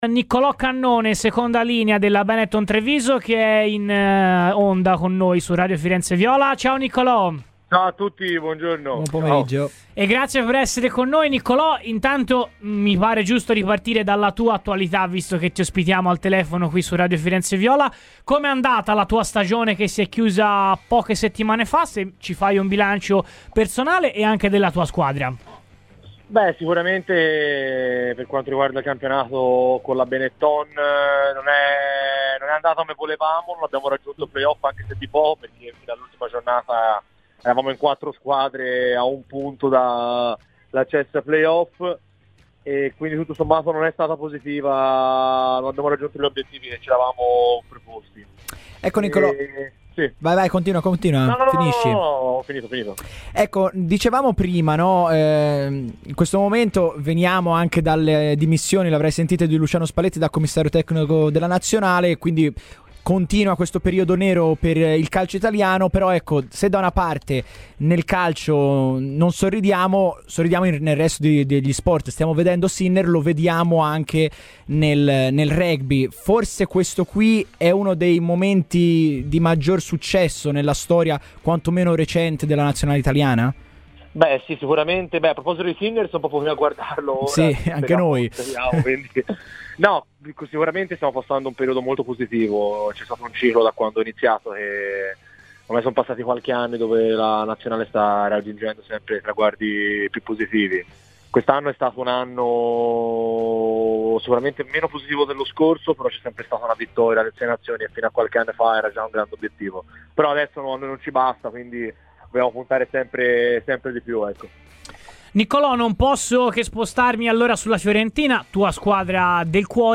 Niccolò Cannone a Radio FirenzeViola